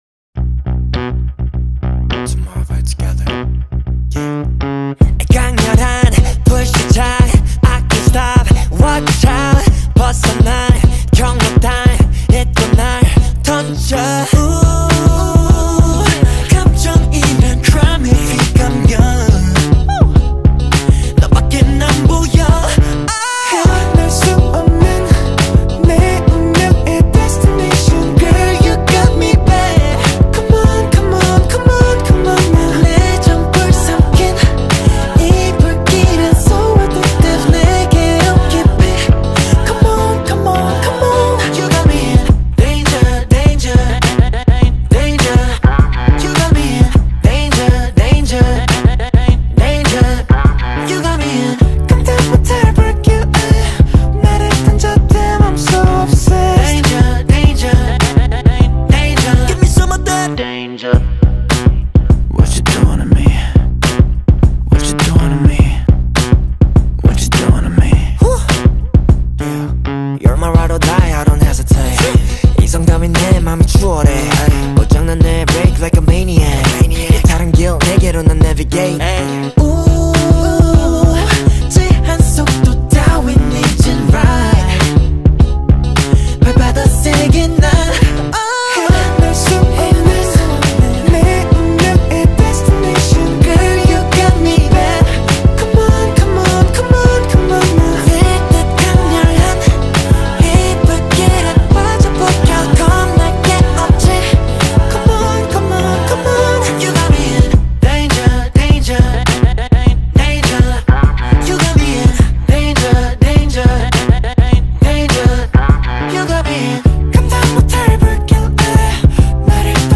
Скачать музыку / Музон / Корейская K-POP музыка 2024